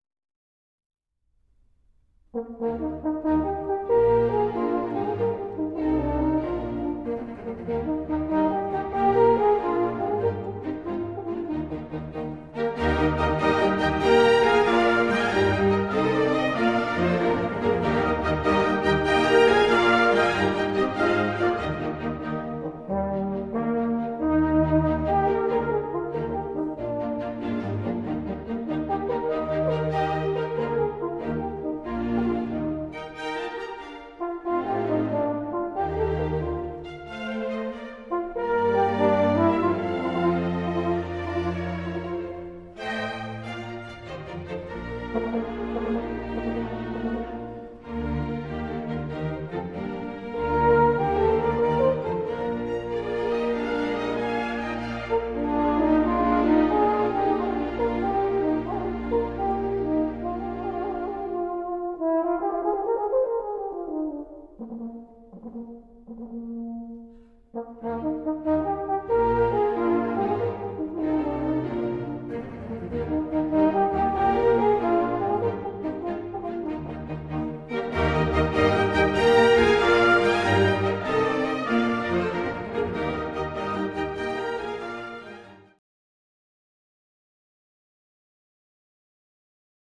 Ce qui caractérise la famille des cuivres, ce n'est pas le matériau, mais le fait qu'ils partagent le même type d'embouchure.
le cor, Mozart, concerto pour cor